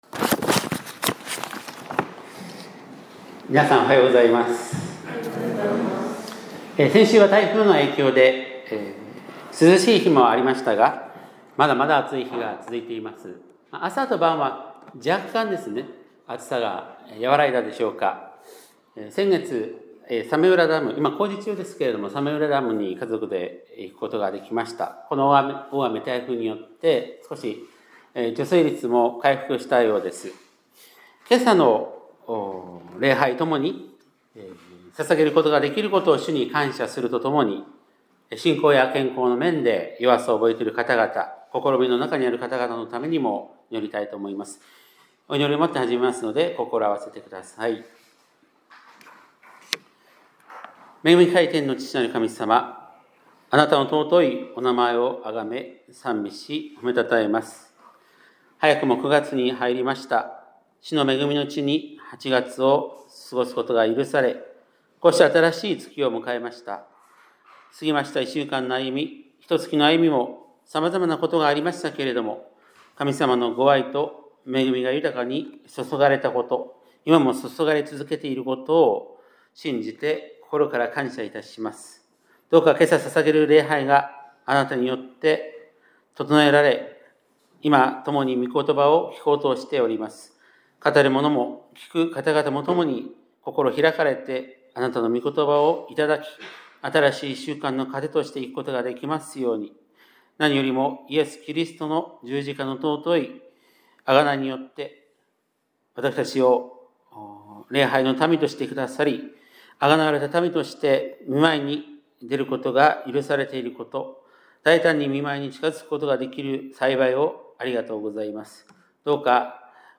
2025年9月7日（日）礼拝メッセージ